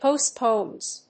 発音記号
• / poˈstponz(米国英語)
• / pəʊˈstpəʊnz(英国英語)